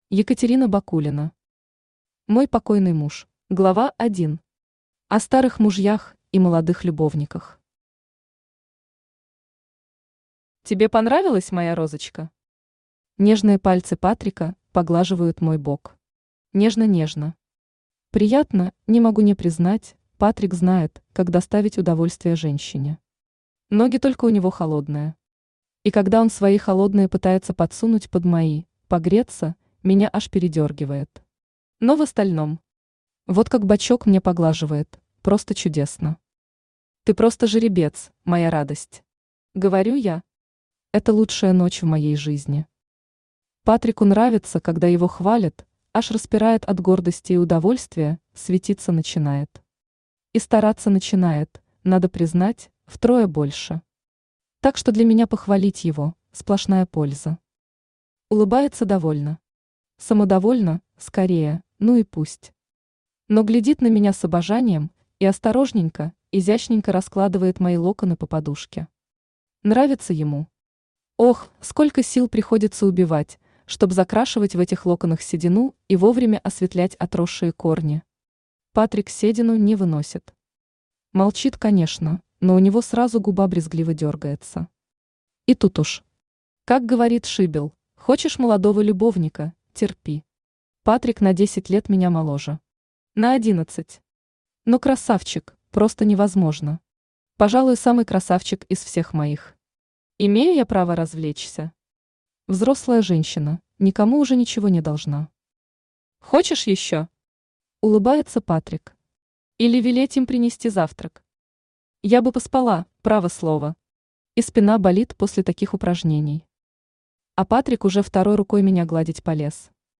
Аудиокнига Мой покойный муж | Библиотека аудиокниг
Aудиокнига Мой покойный муж Автор Екатерина Бакулина Читает аудиокнигу Авточтец ЛитРес.